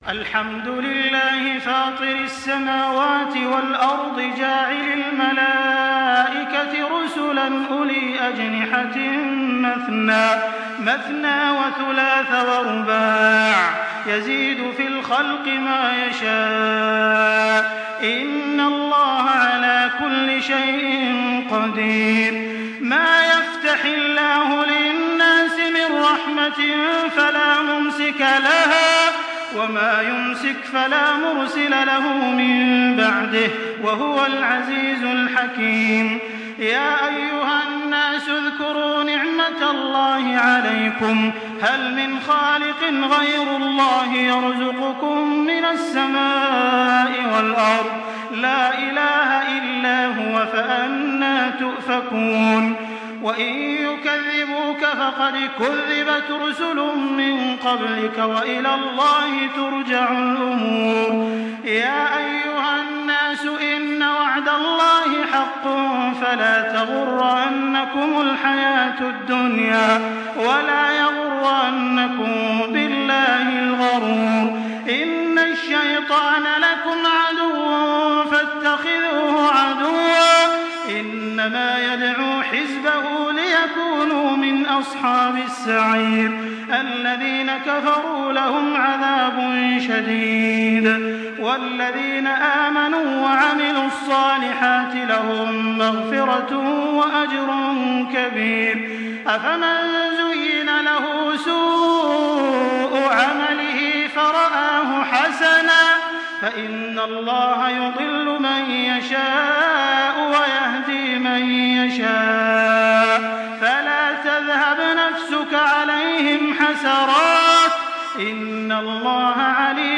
Surah ফাতের MP3 in the Voice of Makkah Taraweeh 1424 in Hafs Narration
Listen and download the full recitation in MP3 format via direct and fast links in multiple qualities to your mobile phone.